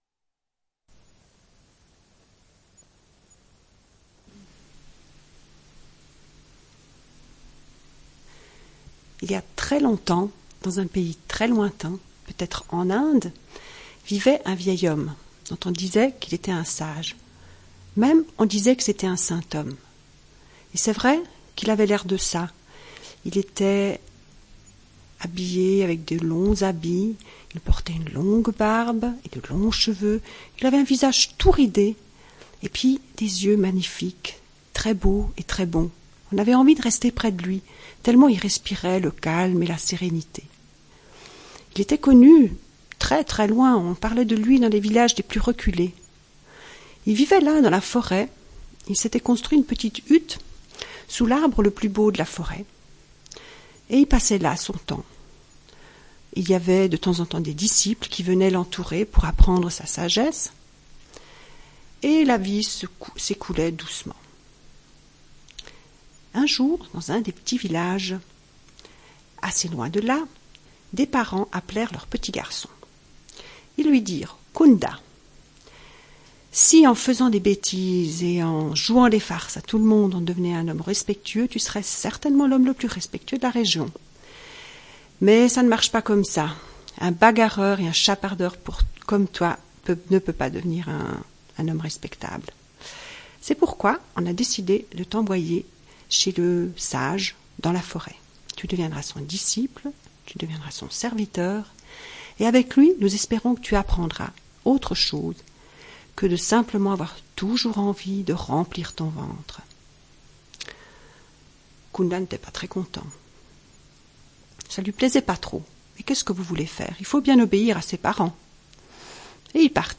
Une cassette audio, face A21:14